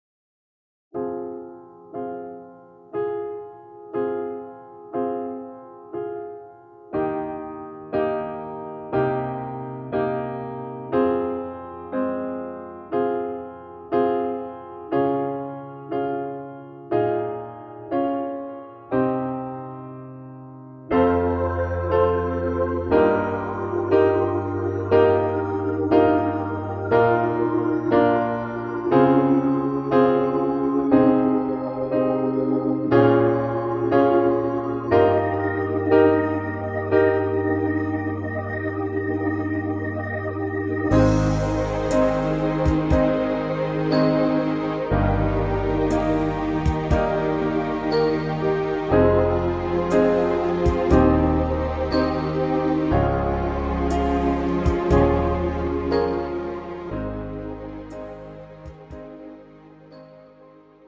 음정 (+1키)
장르 pop 구분